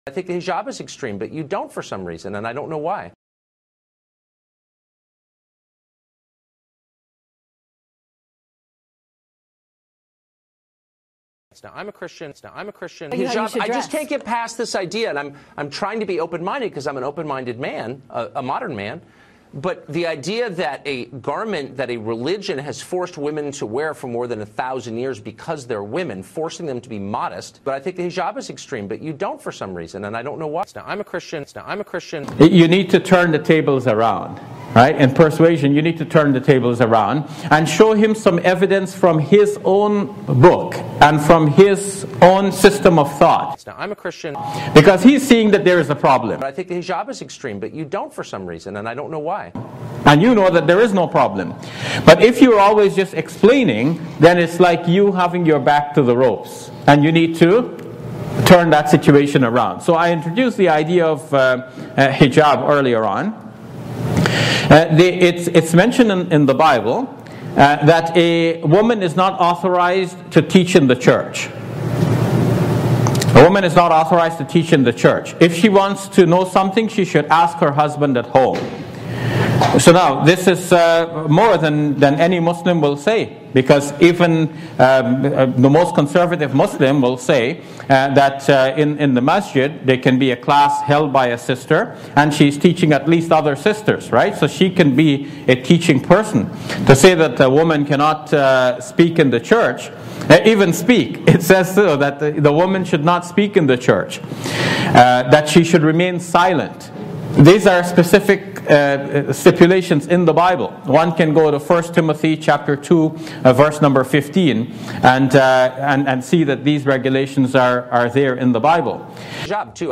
Is this in the BIBLE or QURAN? (With a Christian woman giving Great advice)